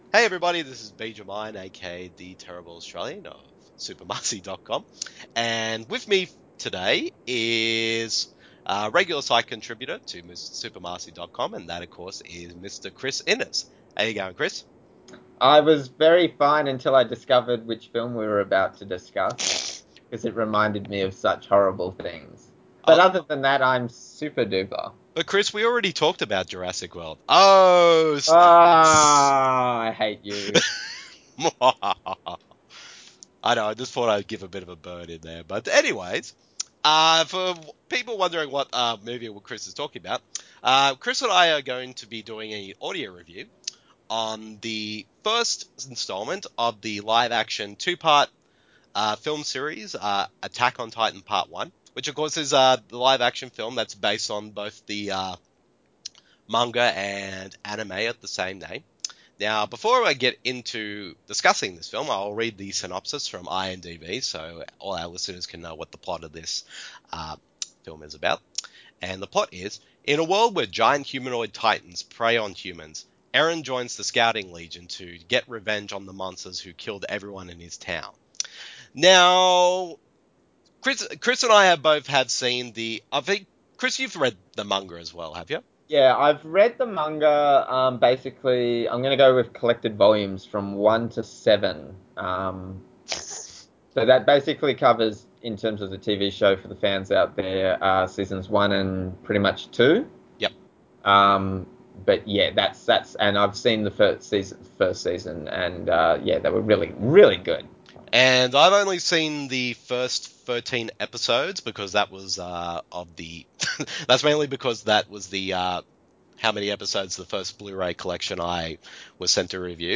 [Audio Review] Attack On Titan
The following review in an audio format, as a back and forth discussion between the two of us.